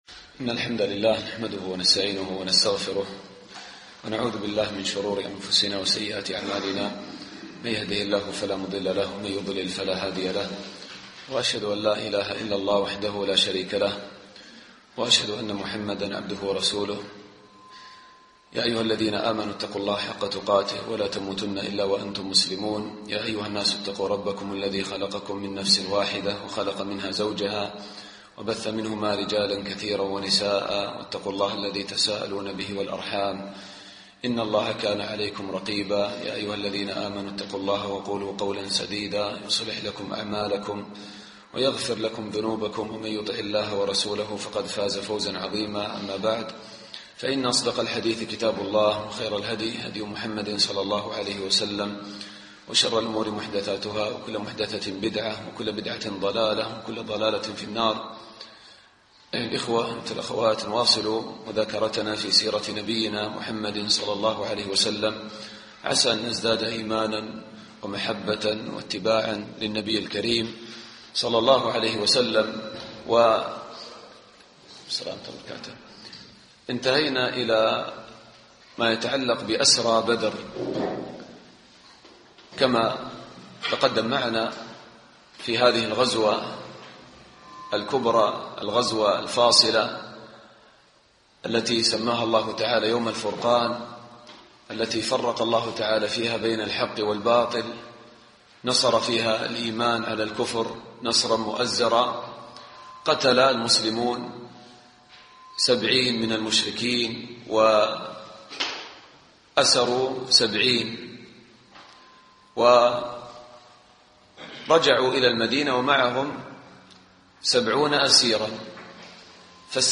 الدرس الحادي عشر